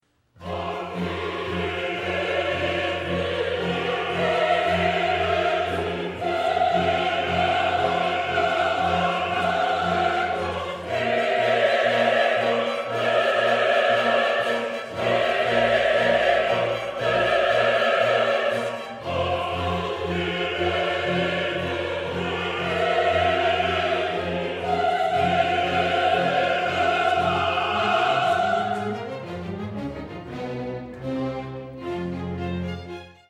Dame Jane Glover, conductor
Music of the Baroque Chorus and Orchestra